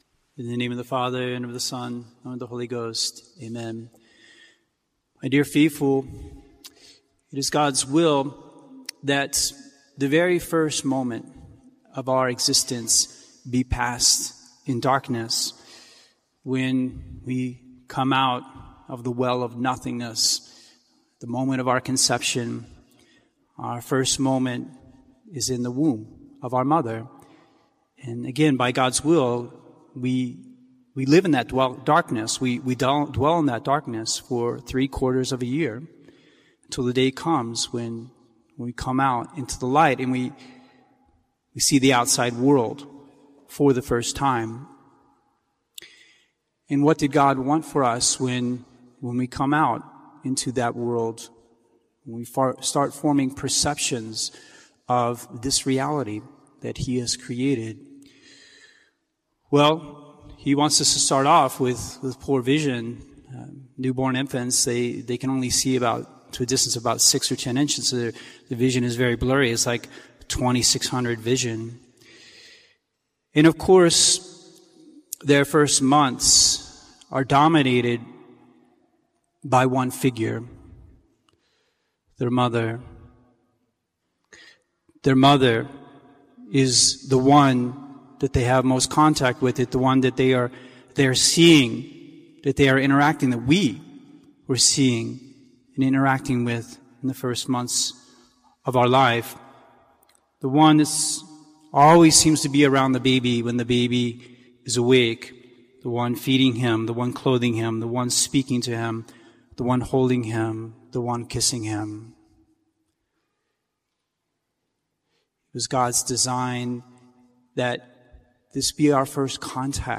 Becoming the Infant of Our Lady, Sermon